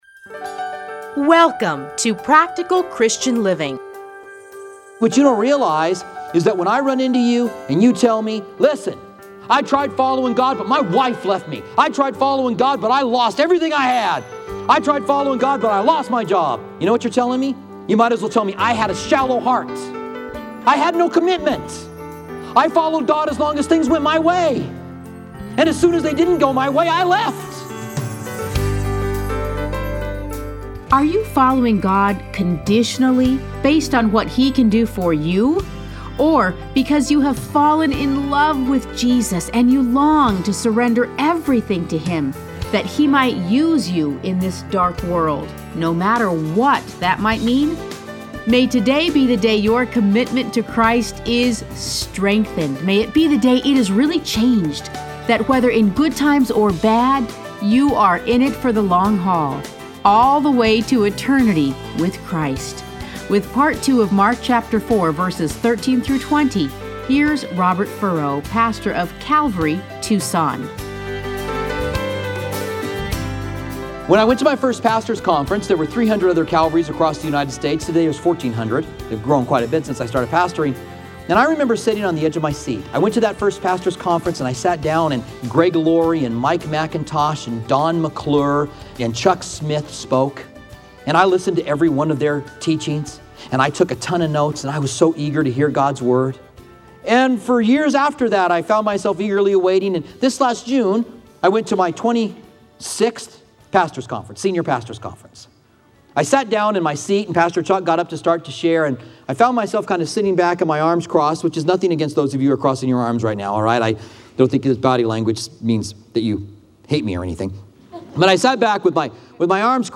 Listen to a teaching from Mark 4:13-20.